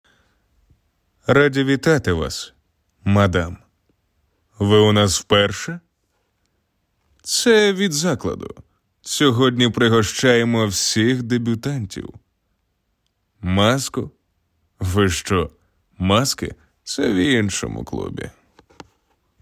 Чоловіча
Баритон